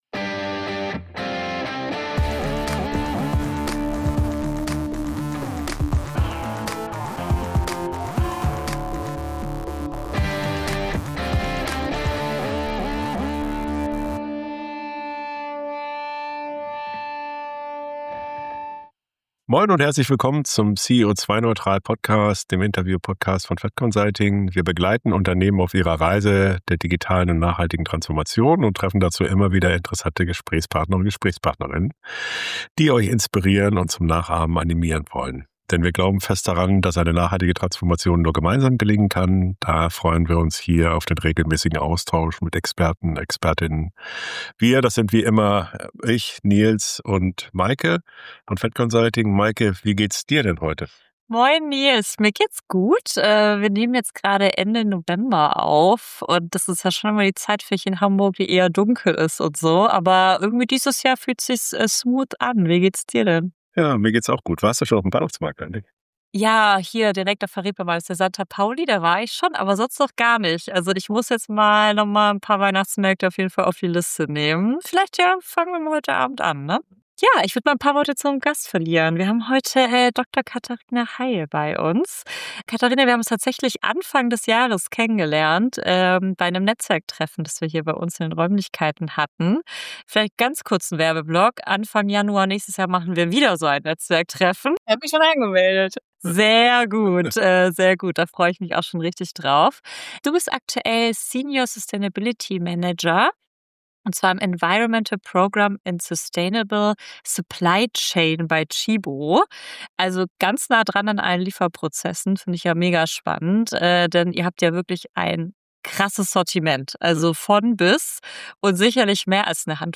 Wirksame Transformation der Lieferkette: Welche Ansätze bei Tchibo wirklich funktionieren ~ CEO2-neutral - Der Interview-Podcast für mehr Nachhaltigkeit im Unternehmen Podcast